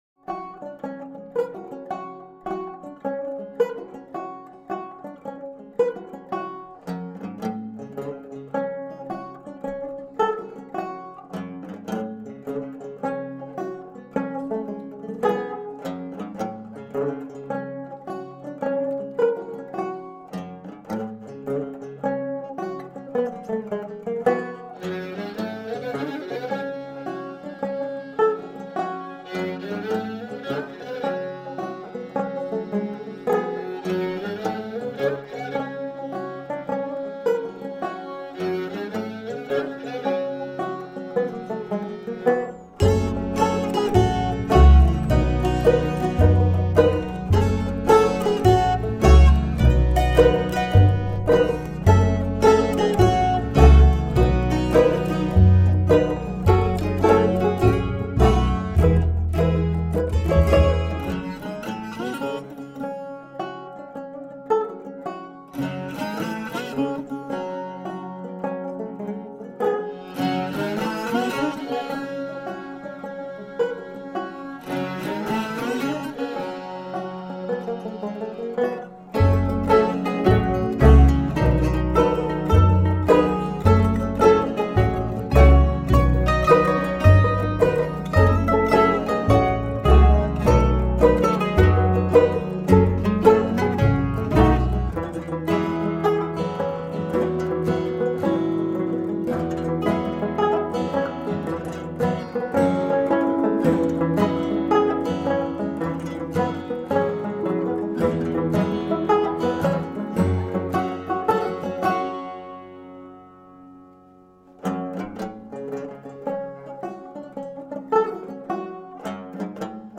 Music played by hand on instruments made of wood.
Tagged as: World, New Age